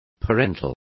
Complete with pronunciation of the translation of parental.